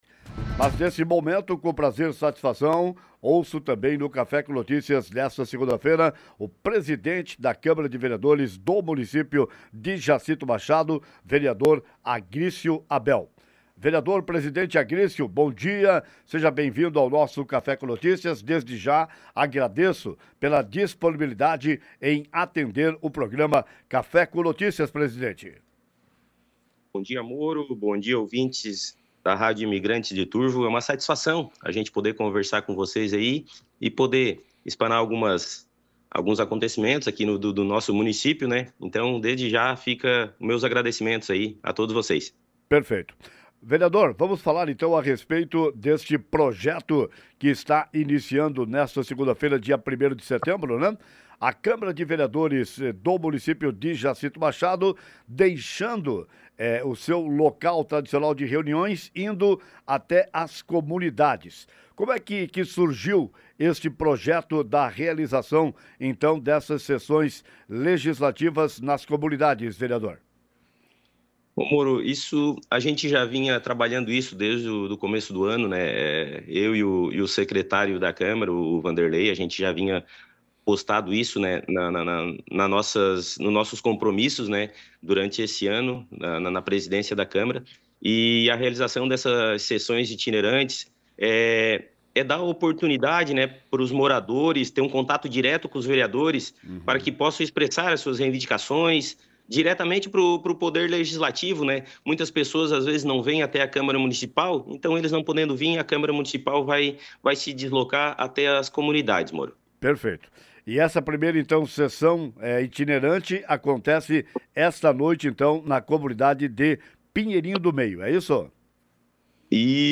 Poder legislativo de Jacinto Machado inicia nesta segunda-feira (1º/09) reuniões itinerantes nas comunidades: Ouça a entrevista com o vereador e atual presidente da Câmara de Vereadores, Agrício Abel (MDB) esta manhã no Café com Notícias:
Entrevista-com-Agricio-Abel.mp3